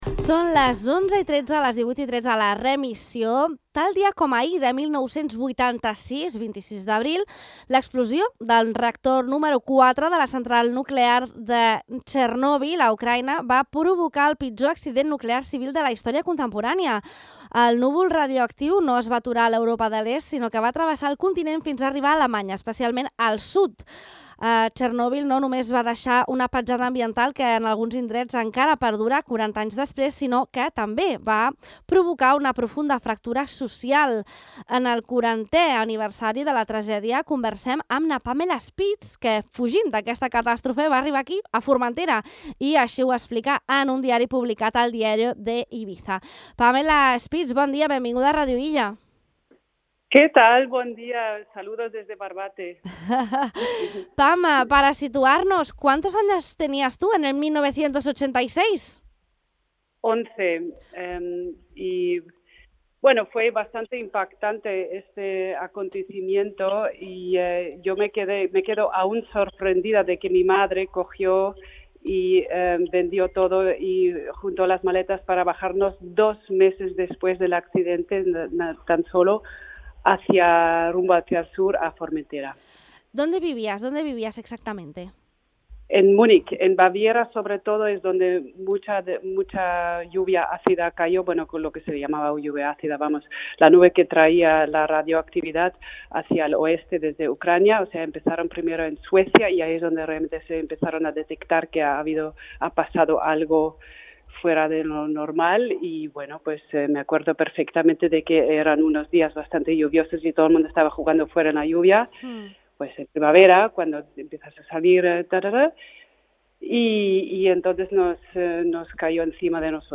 Pluja àcida En una entrevista a Ràdio Illa